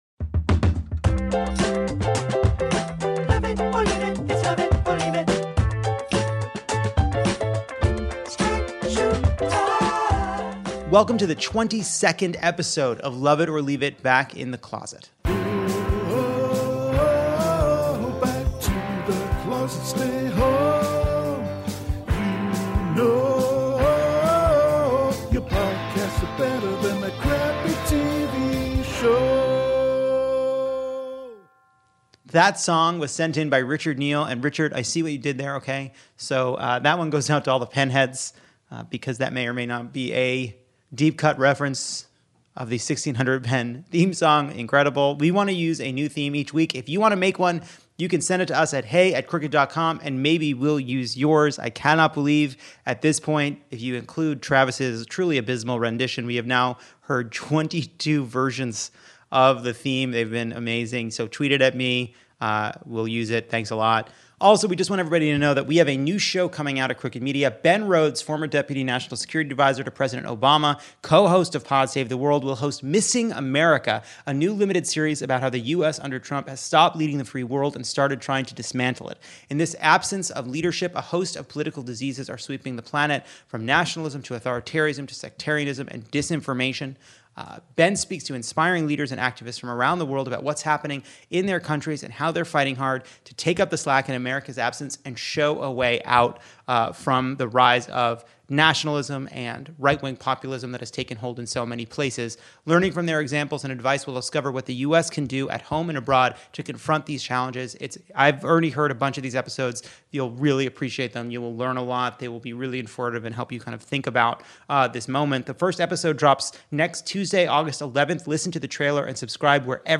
New York's AG sues the NRA and Trump struggles against withering questions like, "What manuals?" Returning champion Naomi Ekperigin joins for the monologue. And Jon talks to Senator Ed Markey and Congressman Joe Kennedy III as they face off in a heated Senate primary, and both agree to face a Massachusetts version of "Queen for A Day."